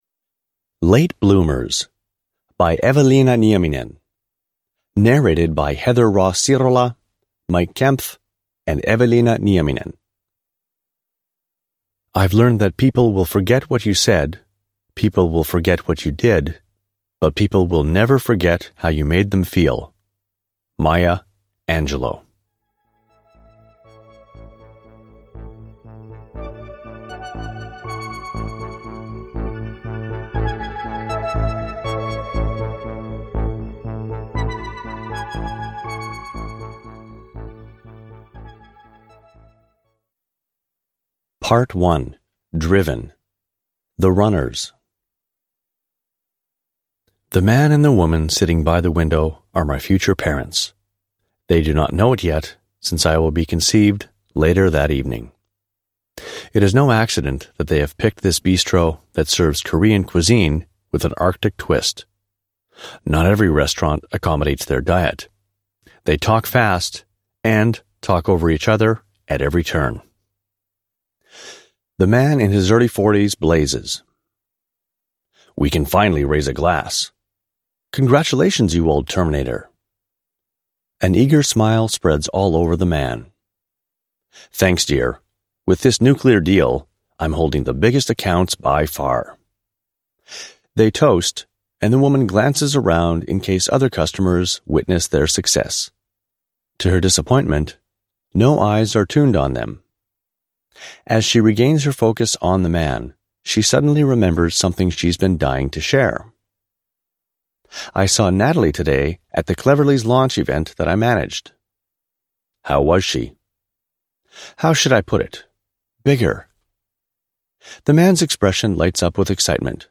Late Bloomers – Ljudbok – Laddas ner